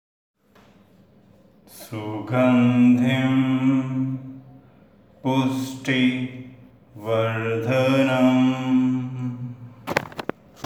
Chanting (slow for comprehension) –